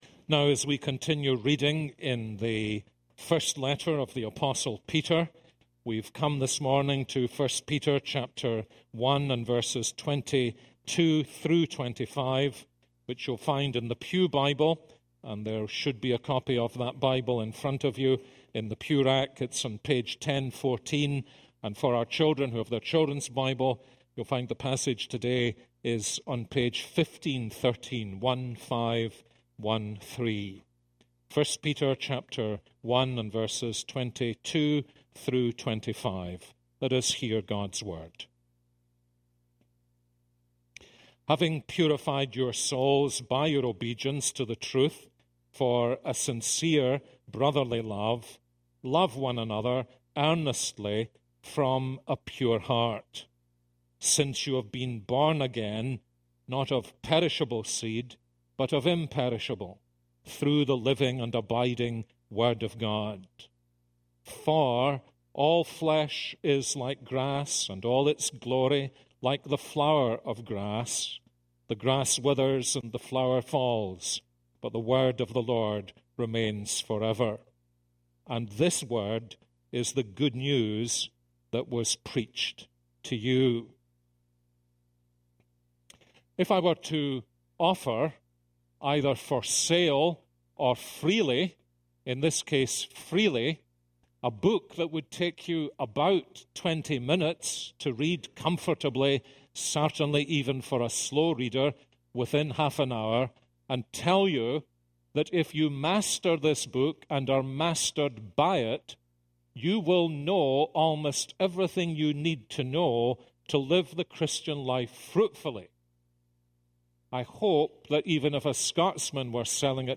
This is a sermon on 1 Peter 1:22-25.